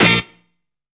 SAX GITA.wav